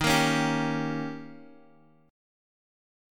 D#7 chord